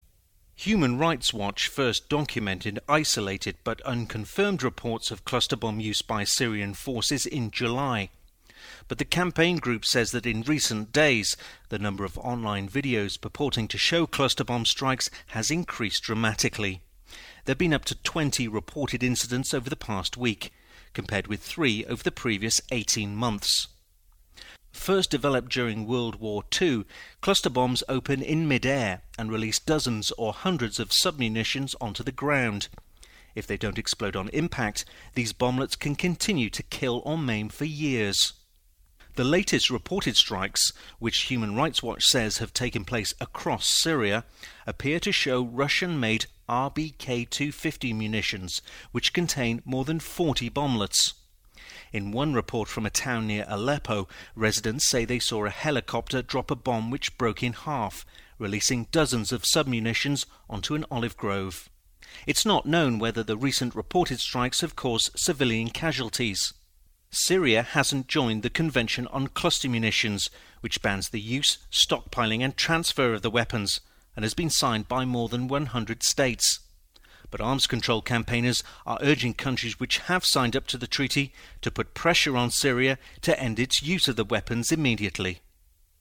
REPORTS FOR BBC NEWS ON A DRAMATIC RISE IN REPORTS OF CLUSTER BOMBS USE BY SYRIA